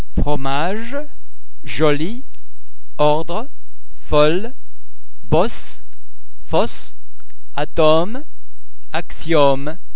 The sound's written representations are: o ô au
o_fromage.mp3